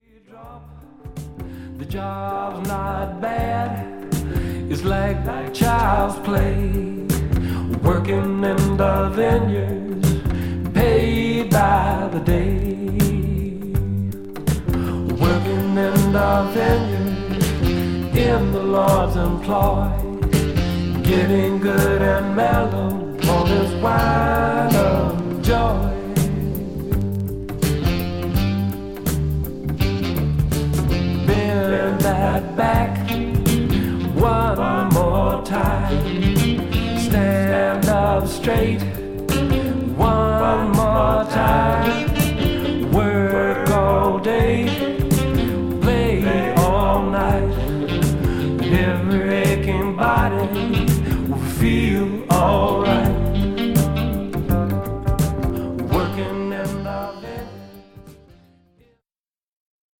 シンプルで温かみのあるメロディをポップ/AOR風からフォーク、ブルースなどのサウンドでまとめた内容です。